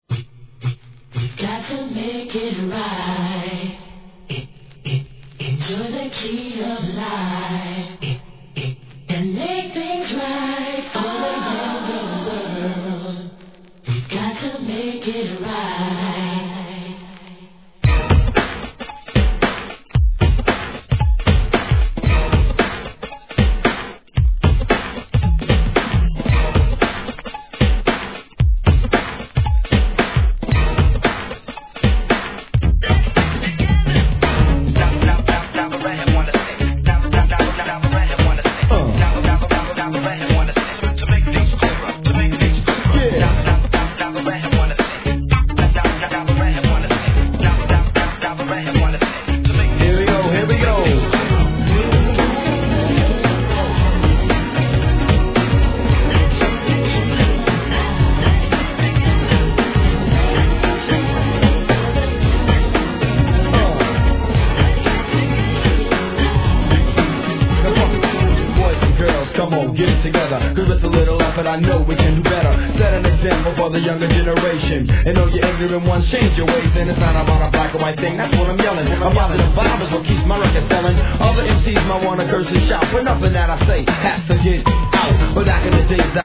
HIP HOP/R&B
NEW JACK SWING!